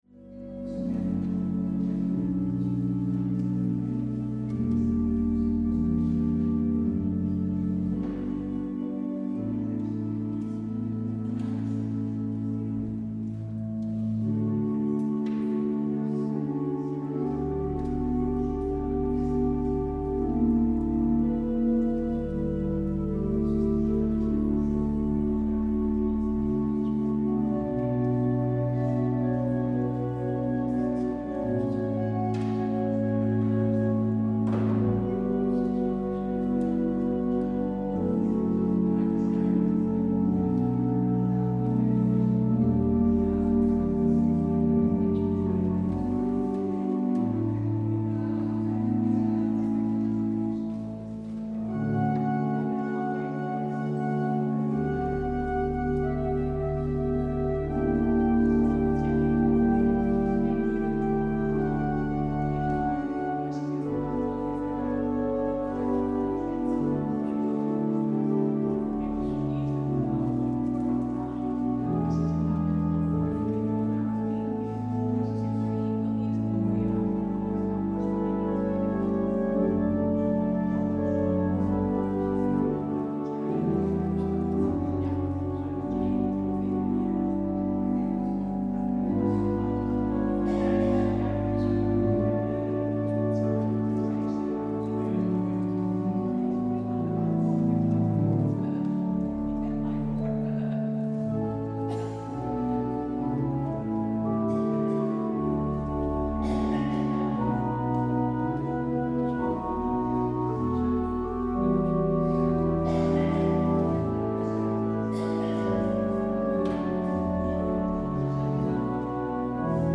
Werkelijk, Ik verzeker u, als een graankorrel niet in de aarde valt en sterft, blijft het één graankorrel, maar wanneer hij sterft brengt hij veel vruchten voort Het openingslied is: Psalm 145: 3 en 5.